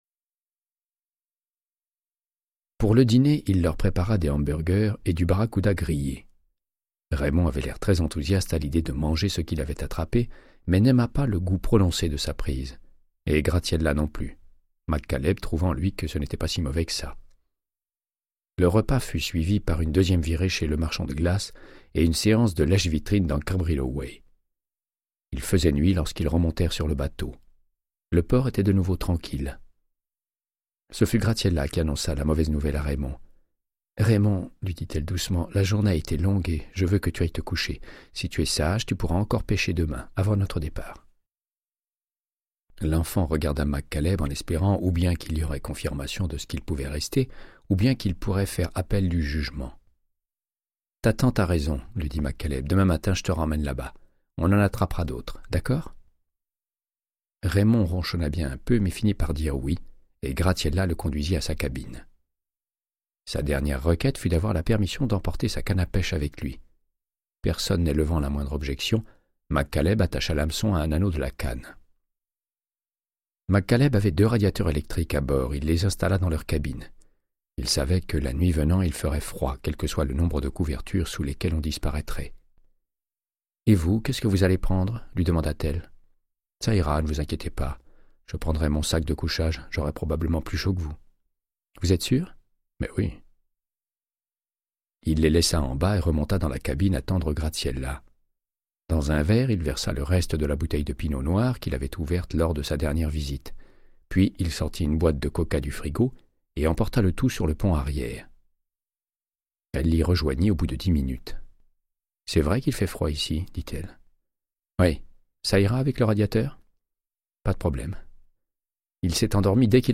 Audiobook = Créance de sang, de Michael Connellly - 90